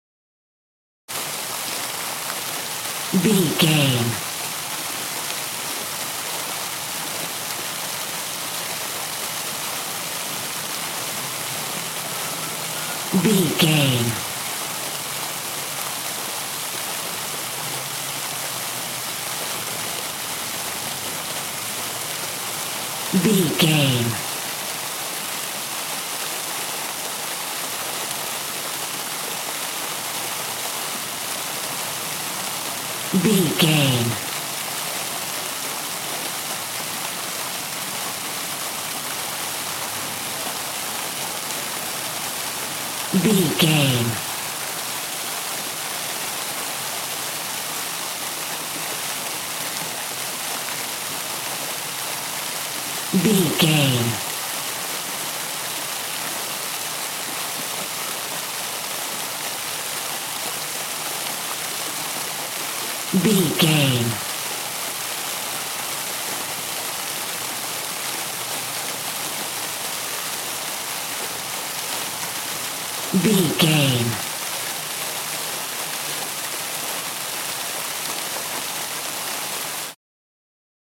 City park fountain
Sound Effects
urban
ambience